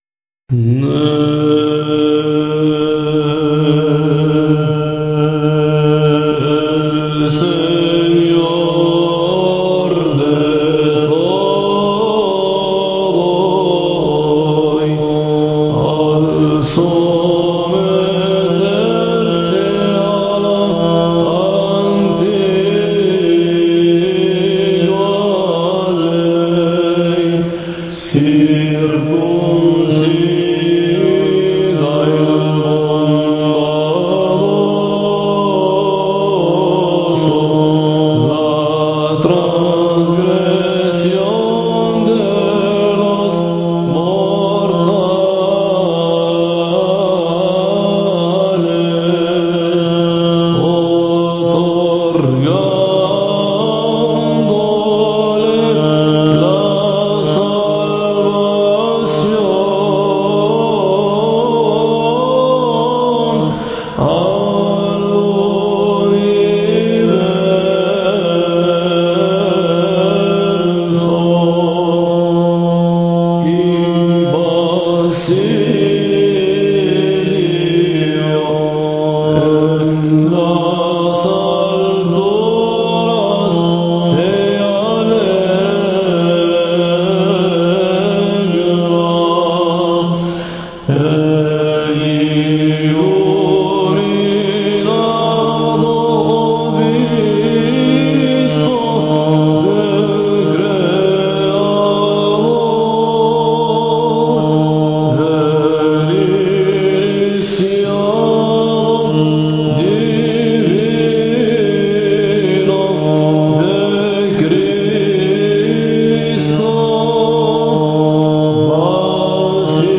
Tono 3